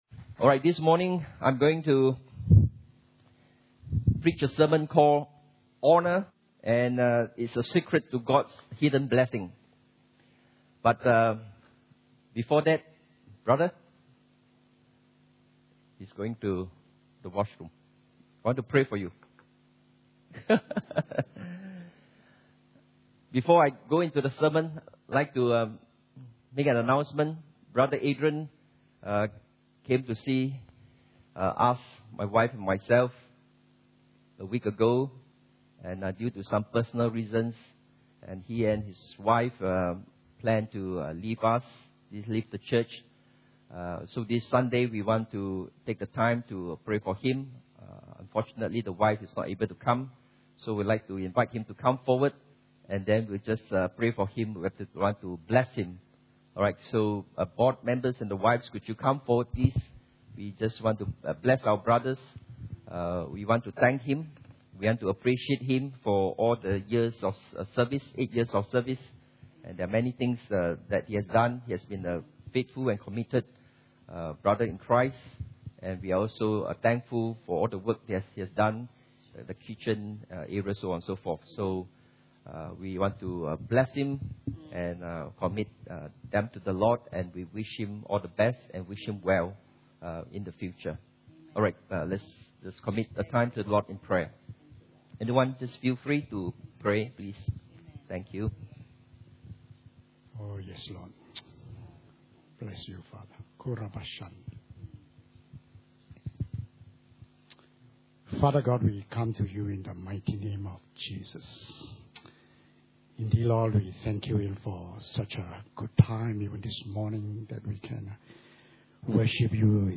Honour Service Type: Sunday Morning « Church Camp 2012 Session 8